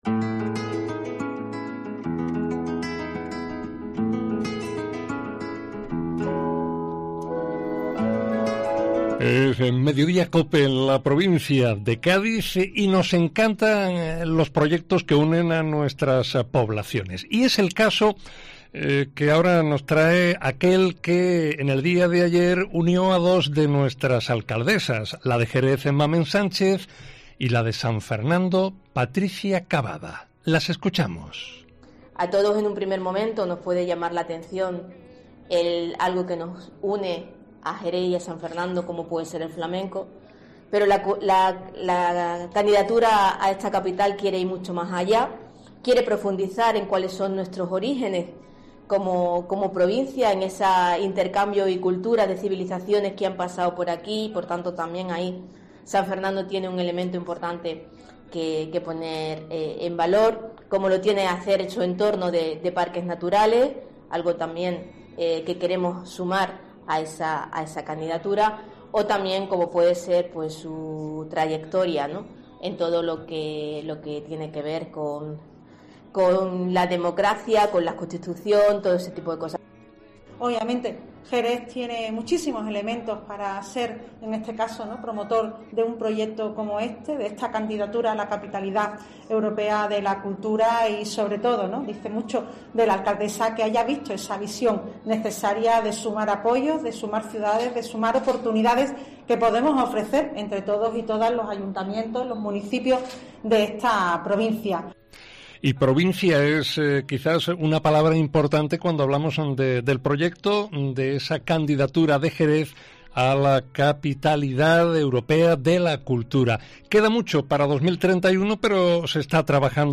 Entrevista Paco Camas, delegado de Cultura del Ayuntamiento de Jerez